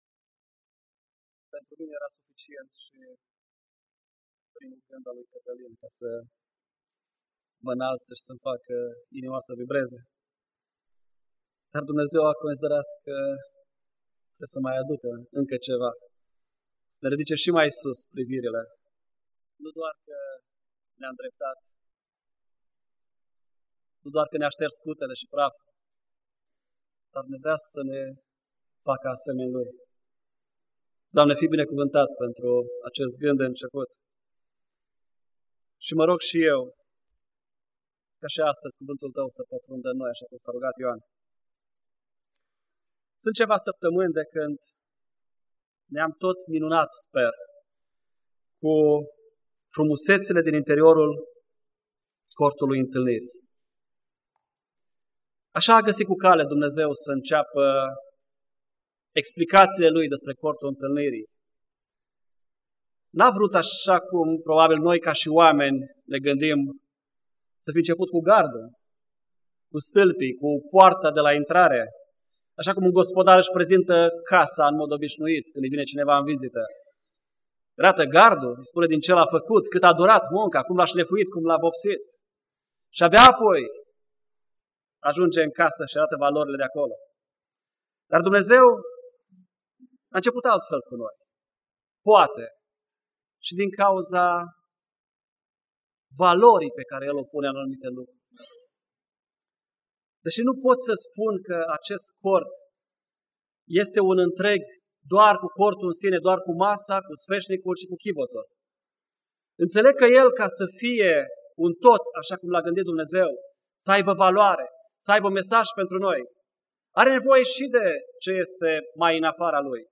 Predica